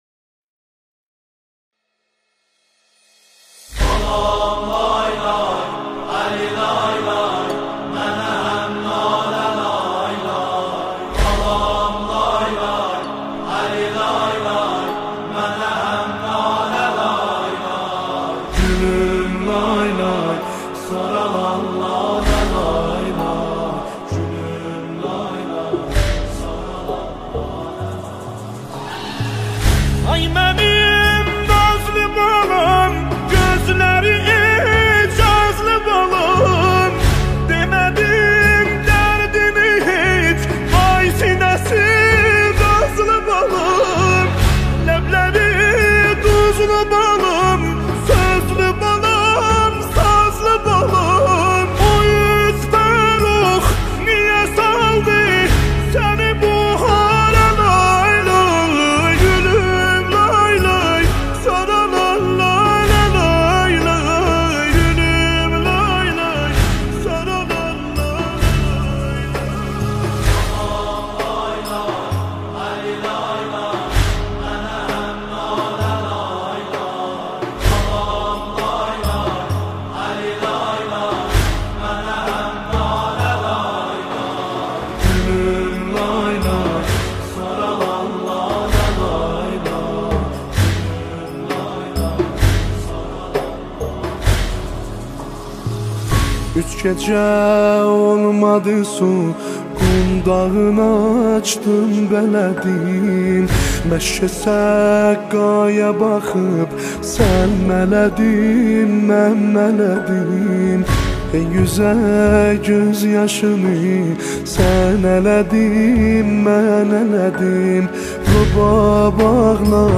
دسته بندی : نوحه ترکی تاریخ : شنبه 31 آگوست 2019